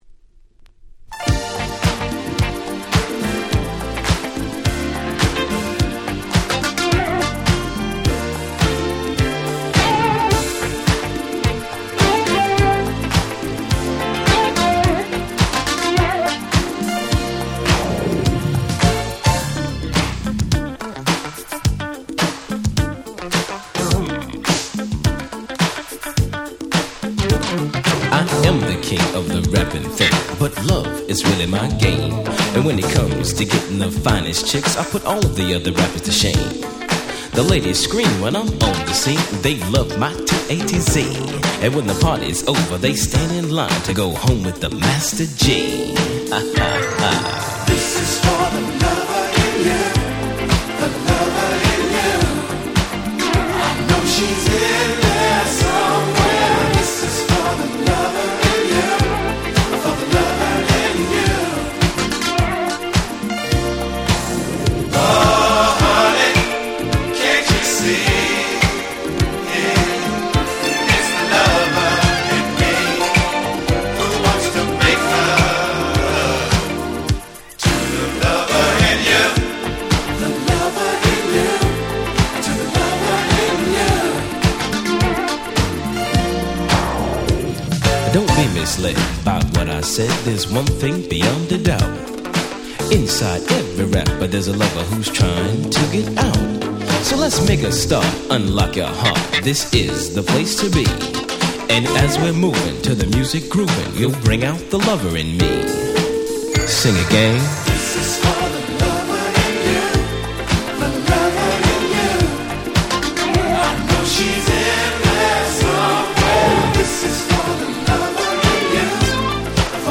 【Media】Vinyl 12'' Single
82' Super Nice Old School Hip Hop / Disco !!
爽やかなメロディーと歌声がダンスフロアを包み込みます！